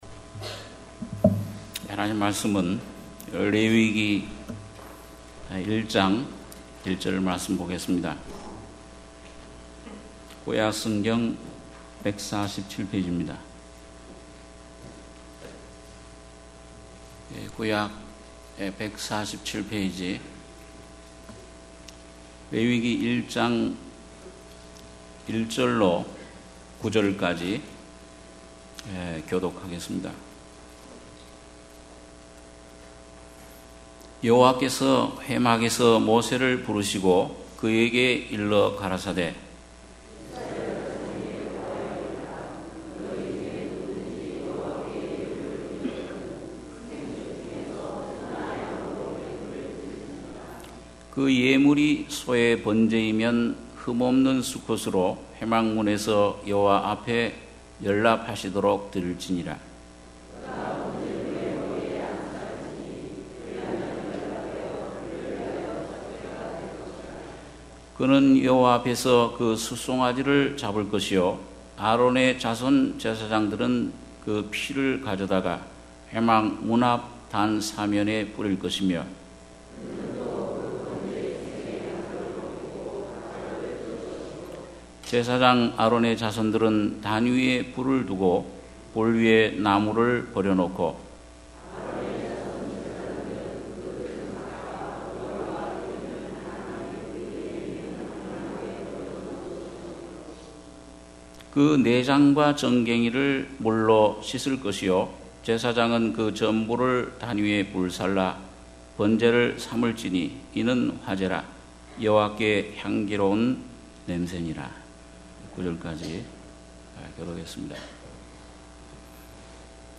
주일예배 - 레위기 1장 1-9절